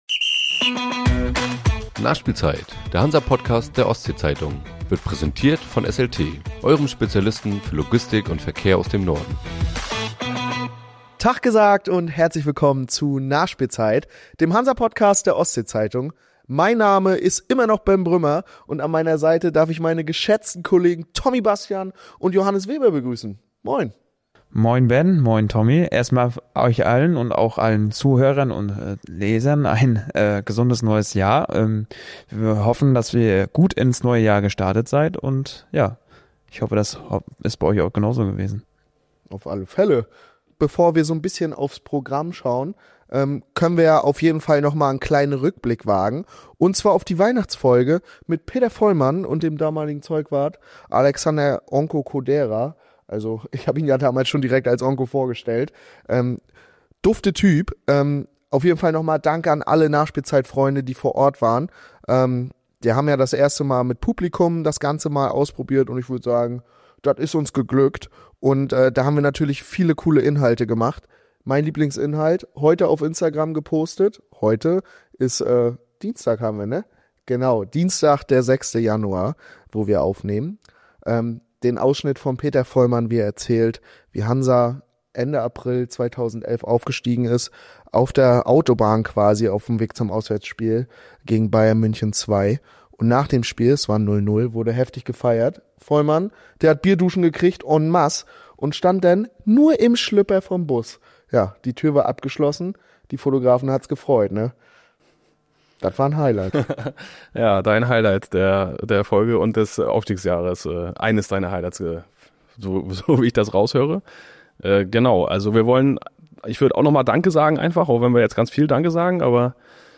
Links hinten in der Abwehr beziehungsweise auf zwei Mittelfeldpositionen sei der FCH nicht optimal aufgestellt, meint das Trio.